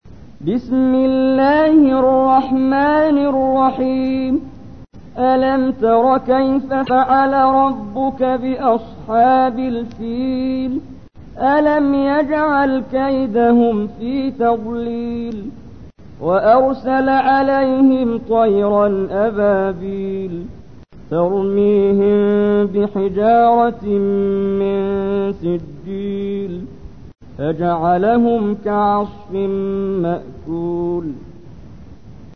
تحميل : 105. سورة الفيل / القارئ محمد جبريل / القرآن الكريم / موقع يا حسين